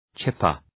Προφορά
{‘tʃıpər}